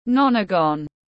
Hình cửu giác tiếng anh gọi là nonagon, phiên âm tiếng anh đọc là /ˈnɒn.ə.ɡən/.
Nonagon /ˈnɒn.ə.ɡən/